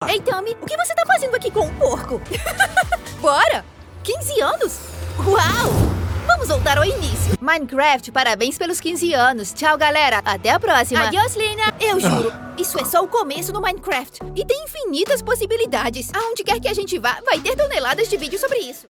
My vocal range spans ages 13 to 40.
HighMezzo-Soprano